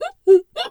pgs/Assets/Audio/Animal_Impersonations/zebra_whinny_06.wav at master
zebra_whinny_06.wav